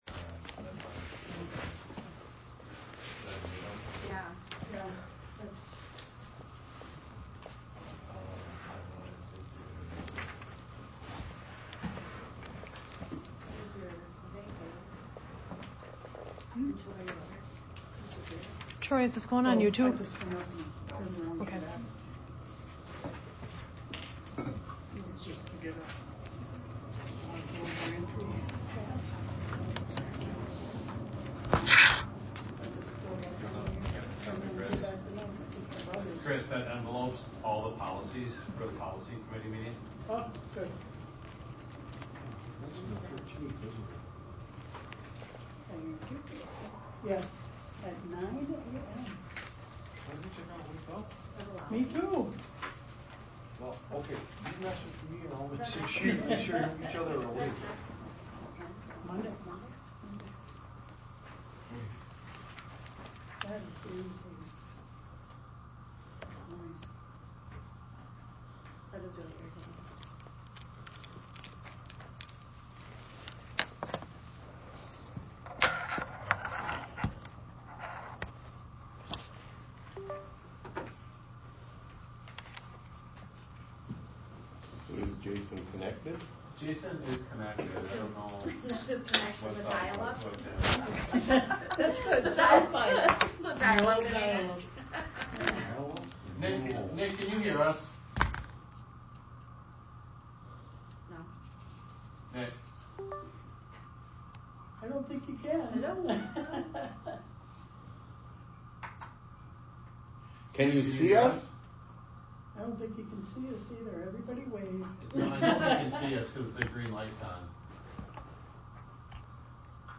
Clintonville High School
So we lost a little bit of the video, but the audio-only recording caught everything.